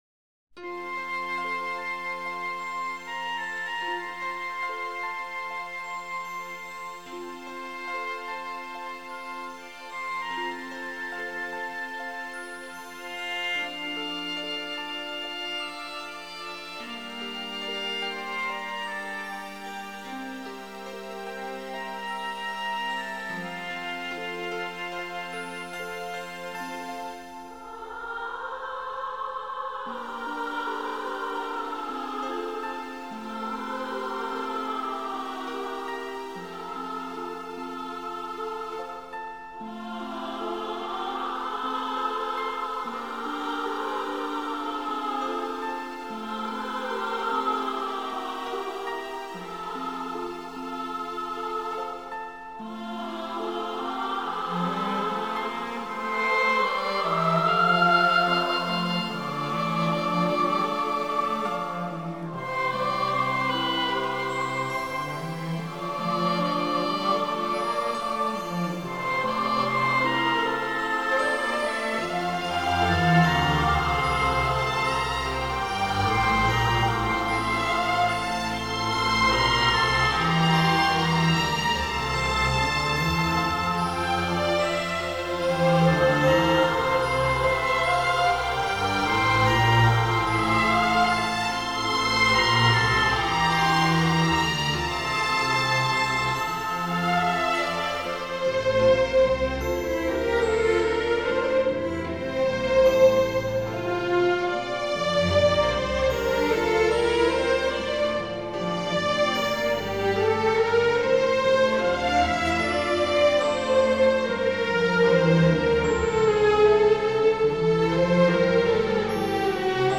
本辑是青海民歌改编的轻音乐。中国民族乐器为主奏，辅以西洋管弦乐器及电声乐器。
最新数码系统录制，堪称民乐天碟。